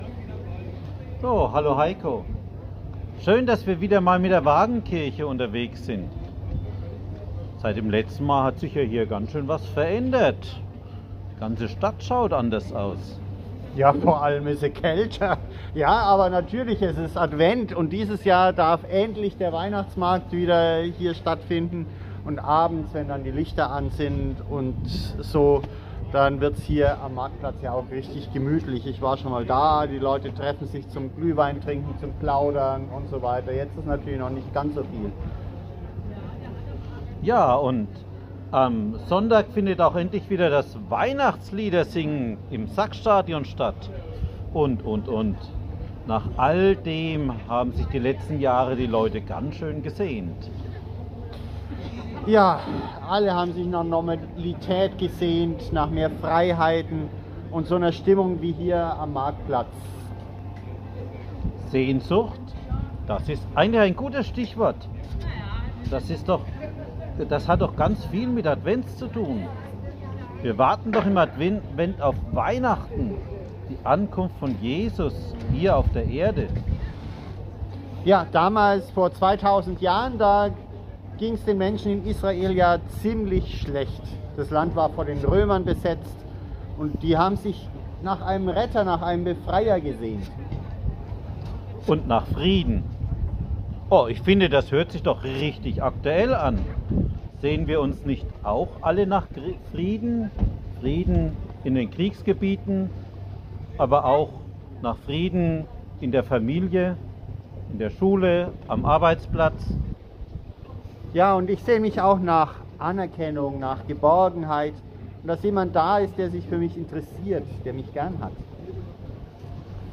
Kurze Impulse zum Nachdenken fürs Wochenende.